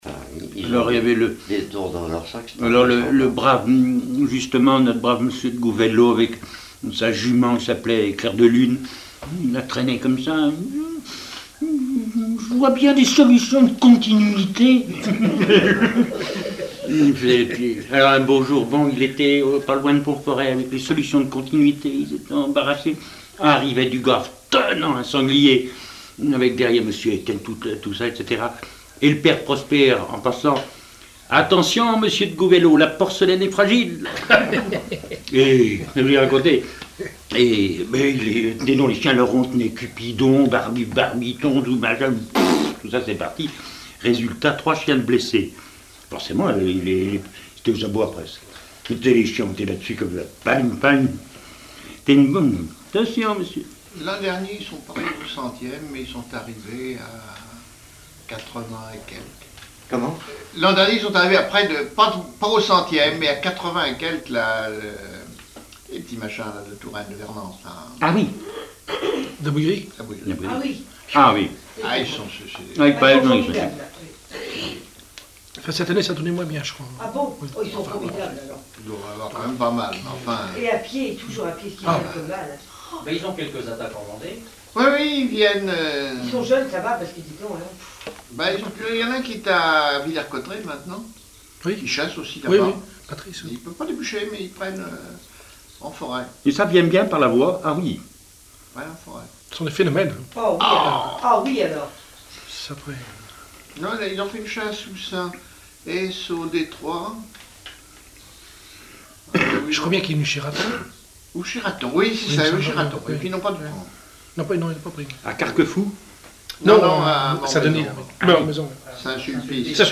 Conversation sur les familles de chassuers
Catégorie Témoignage